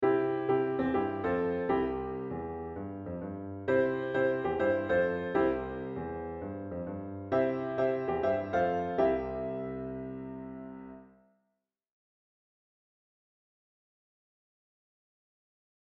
For example, the following short excerpt shows three different ways to play around with the C and F chord, each one followed by a higher voicing (
Different voicings of C and F chords
As you can hear, each time the voicing of the chords moves higher, you get a different impression of the sound of the chords.
revoicing.mp3